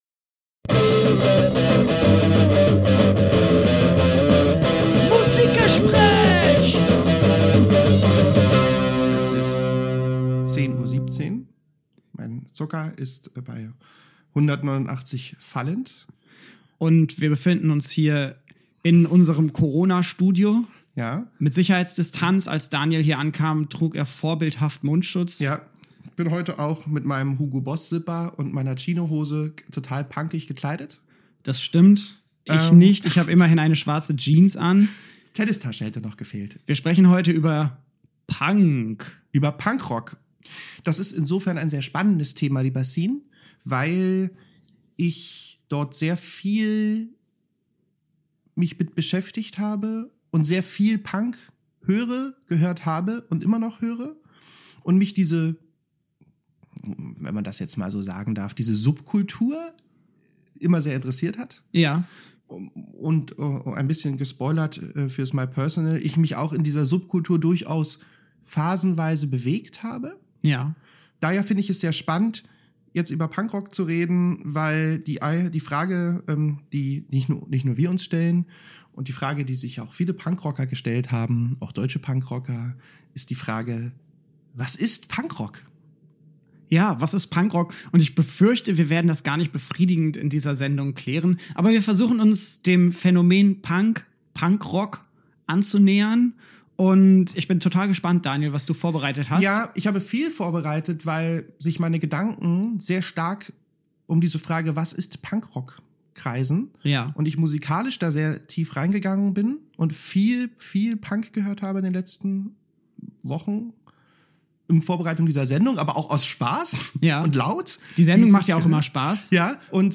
Diesmal wird es laut: im Musikgespräch über Punkrock erfahren wir, wie Musik und Gesellschaft sich gegenseitig beeinflussen und was ABBA mit Punk zu tun hat.